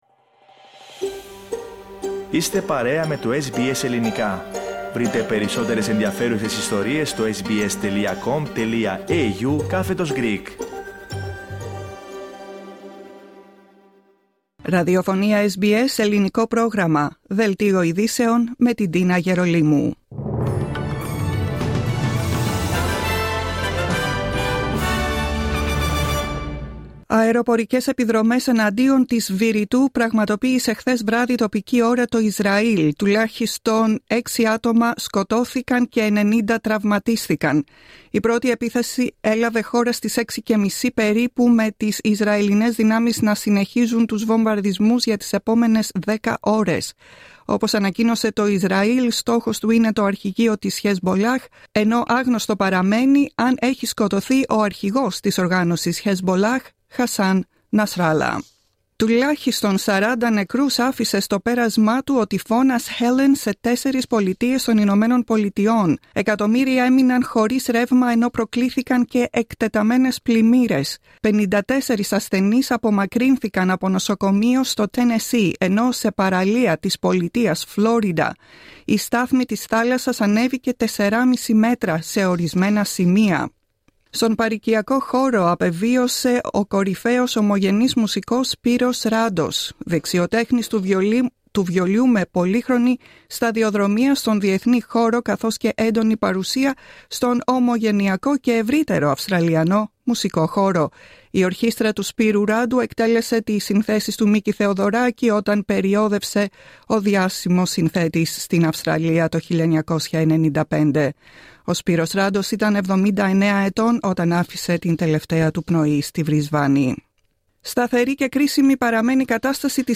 Δελτίο ειδήσεων Σάββατο 28 Σεπτεμβρίου 2024